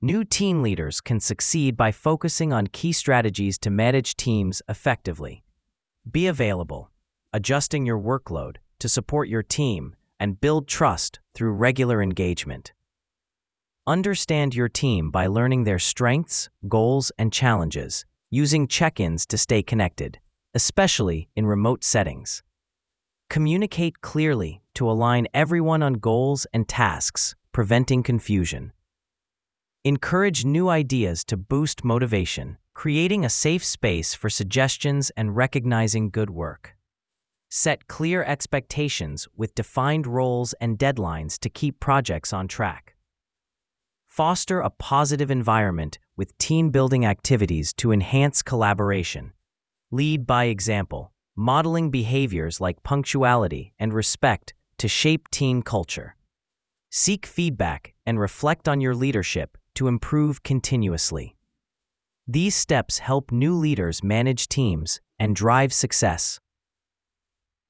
Audio_summary_of_tips_for_new_leaders.mp3